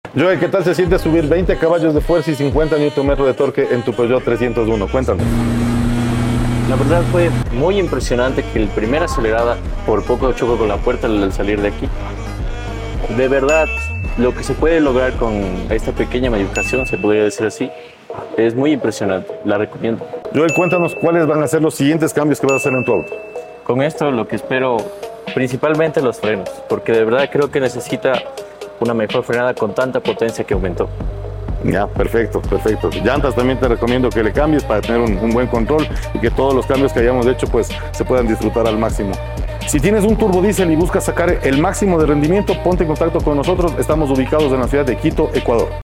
Escucha el testimonio del dueño de este Peugeot 301 turbodiesel, quien nos cuenta sobre su experiencia con el proceso de repotenciación. Después de confiar en nosotros para instalar un sistema de escape de alto rendimiento, su vehículo ha ganado potencia y mejorado significativamente en rendimiento.